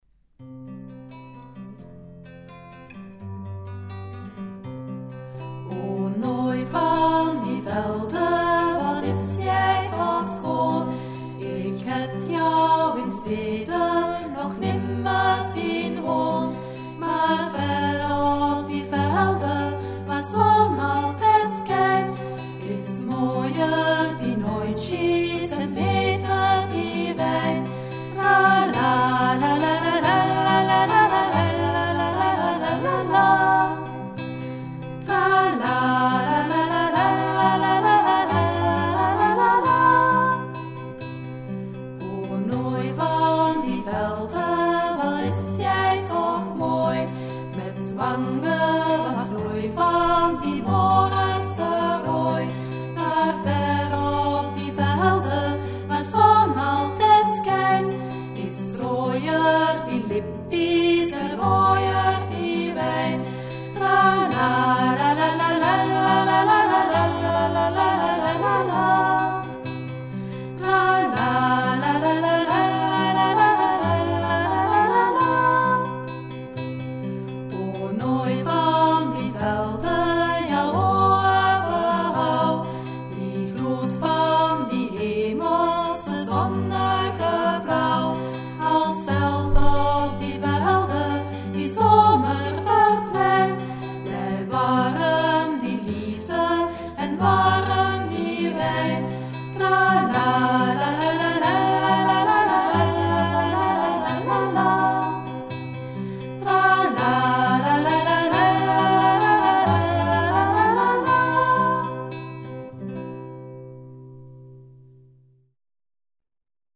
Zuid-Afrikaans volksliedje